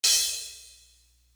Crashes & Cymbals
Real Mobb Do Crash.wav